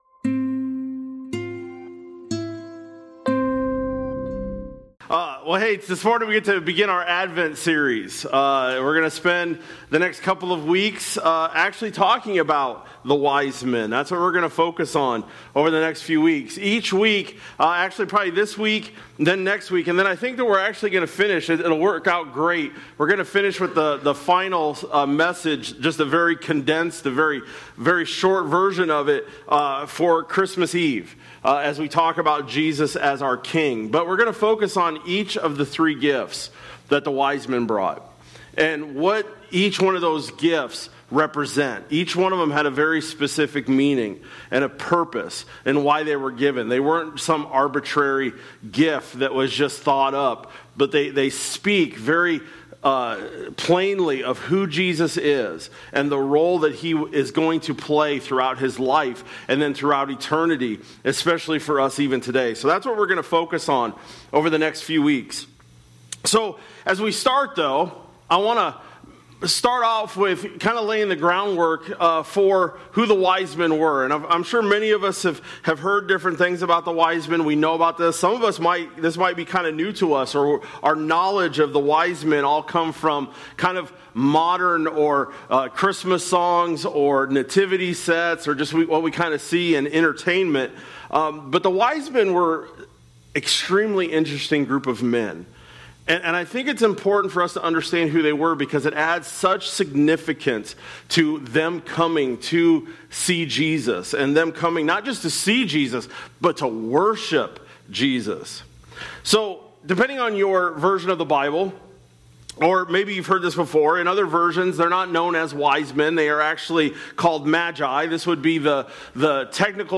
Dec-14-25-Sermon-Audio.mp3